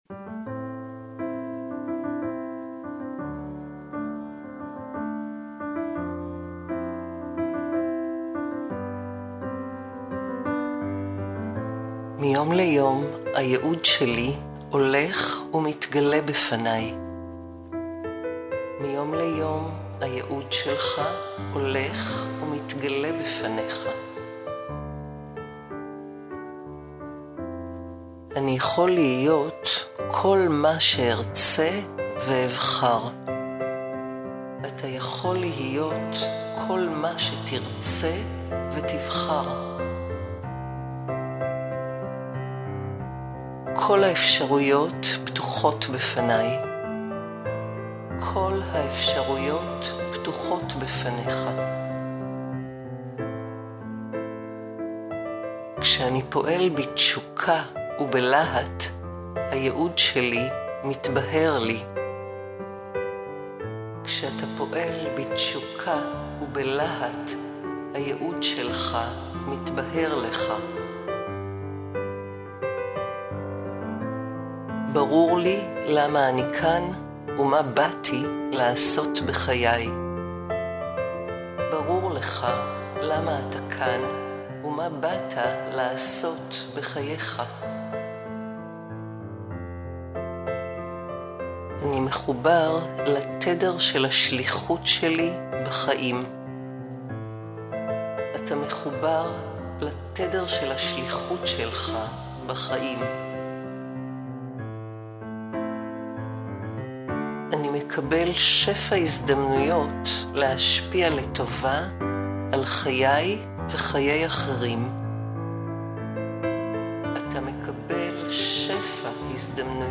• בנוסף לקלטות הסאבלימינליות, תקבלו גם קובץ של המסרים הגלויים, כפי שהוקלטו במקור בשילוב מוזיקה נעימה.
דוגמה מהצהרות הגלויות למציאת היעוד לגברים:
למצוא את היעוד בחיים לגברים – מדיטציה גלויה